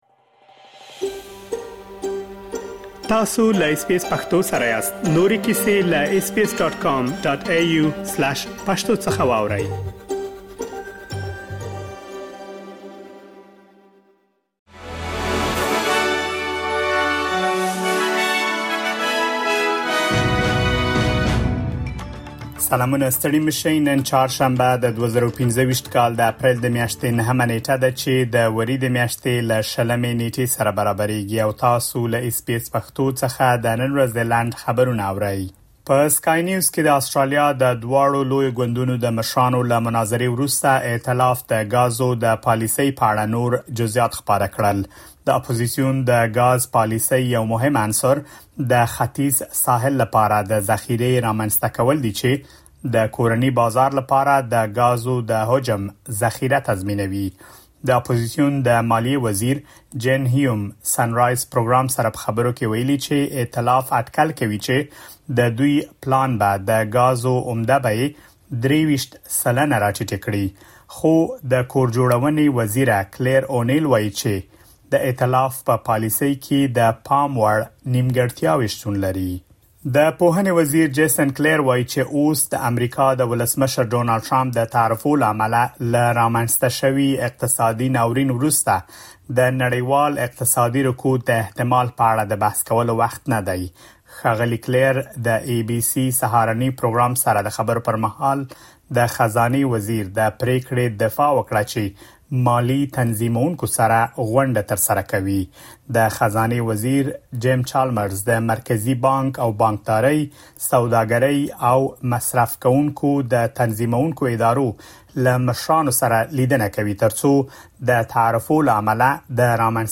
د اس بي اس پښتو د نن ورځې لنډ خبرونه | ۹ اپریل ۲۰۲۵
د اس بي اس پښتو د نن ورځې لنډ خبرونه دلته واورئ.